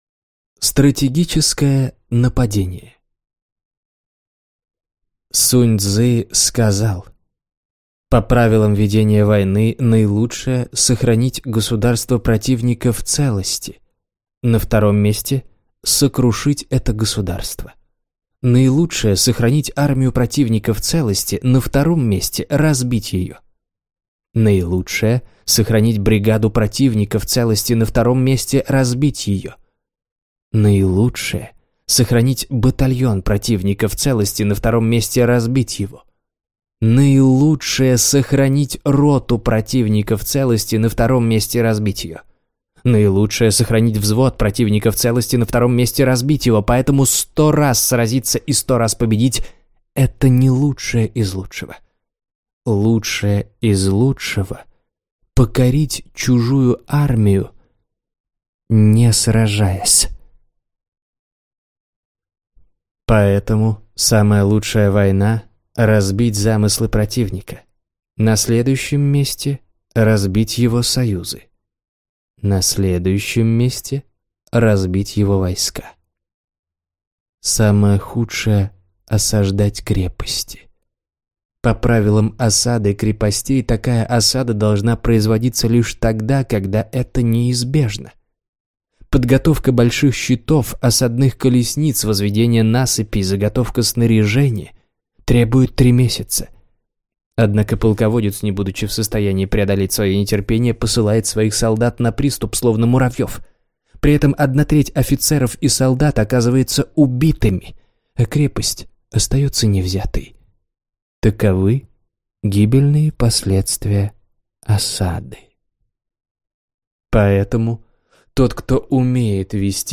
Аудиокнига Трактат о военном искусстве | Библиотека аудиокниг